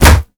punch_heavy_huge_distorted_02.wav